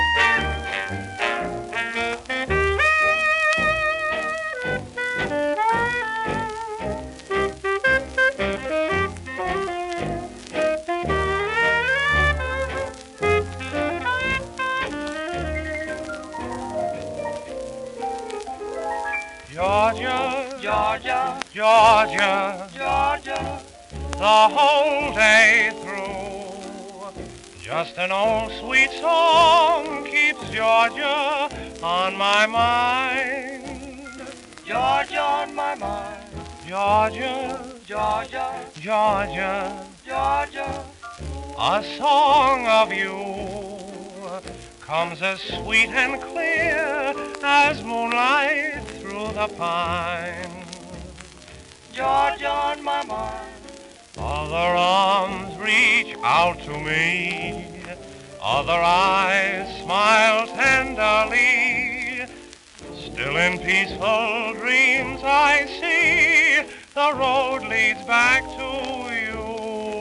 盤質:B+ *面擦れ、小キズ
1931年シカゴ録音